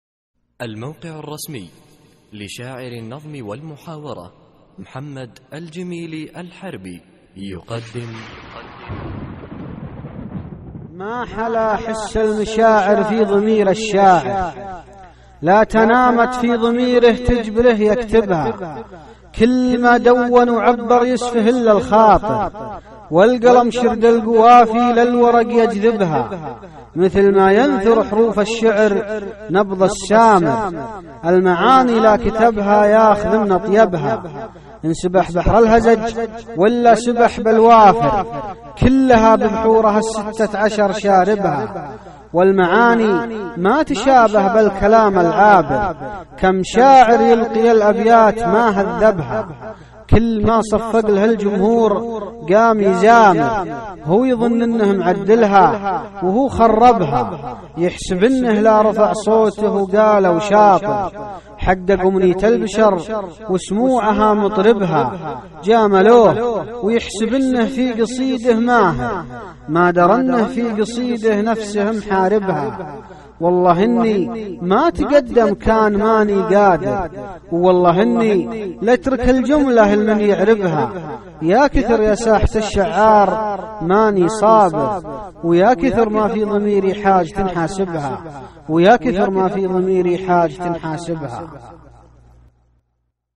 القصـائــد الصوتية
اسم القصيدة : نبض السامر ~ إلقاء